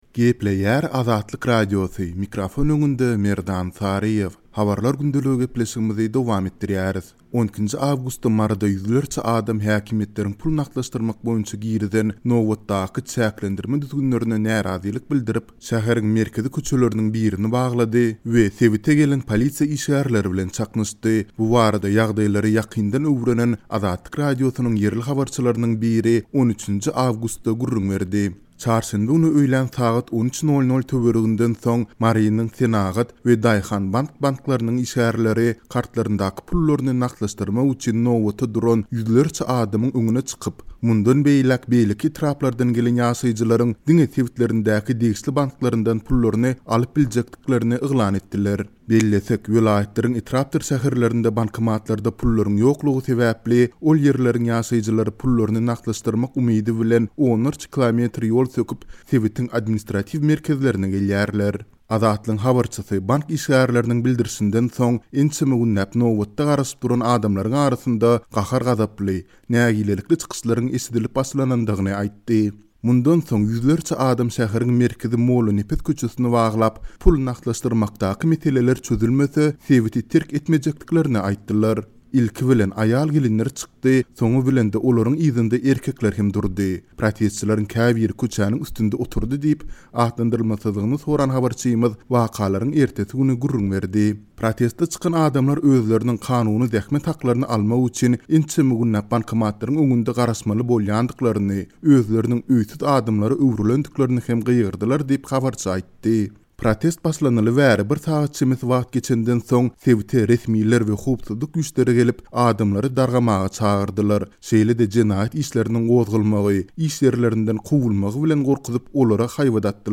12-nji awgustda Maryda ýüzlerçe adam, häkimiýetleriň pul nagtlaşdyrmak boýunça girizen nobatdaky çäklendirme-düzgünlerine närazylyk bildirip, şäheriň merkezi köçeleriniň birini baglady we sebite gelen polisiýa işgärleri bilen çaknyşdy. Bu barada ýagdaýlary ýakyndan öwrenen Azatlyk Radiosynyň ýerli habarçylarynyň biri 13-nji awgustda gürrüň berdi.